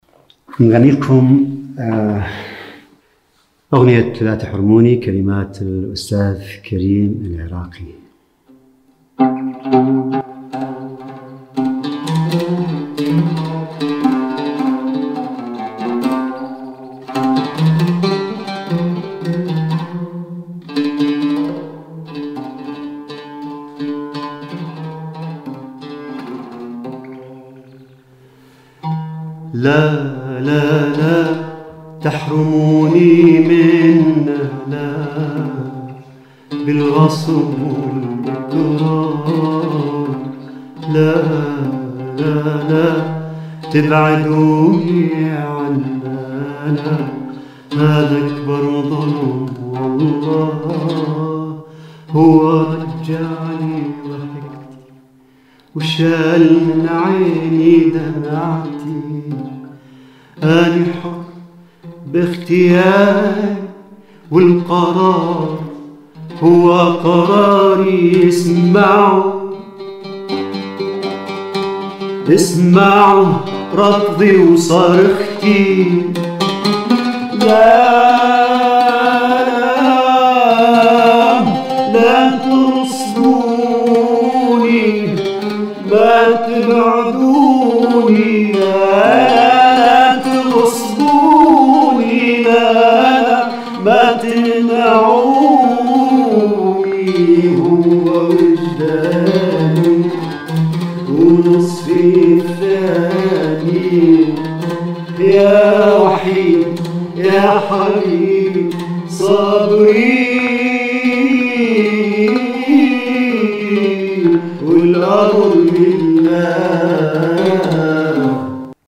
على العود